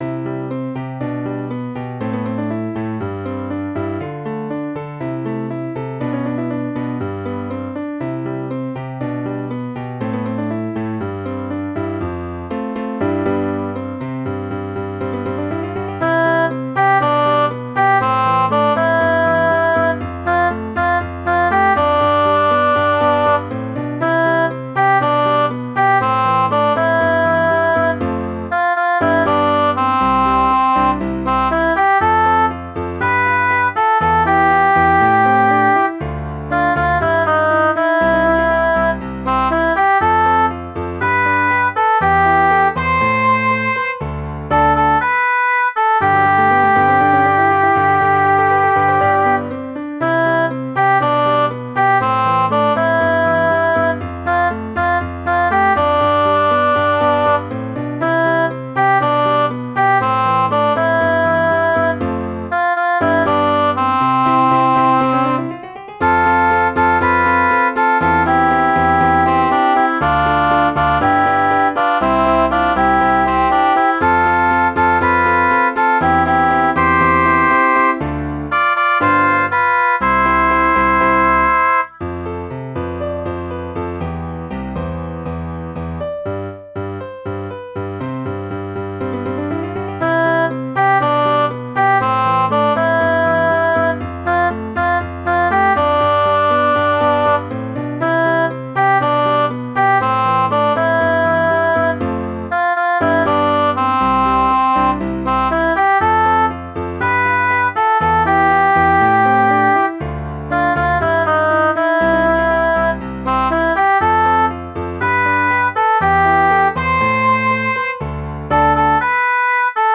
校歌
♪校歌の伴奏が流れます♪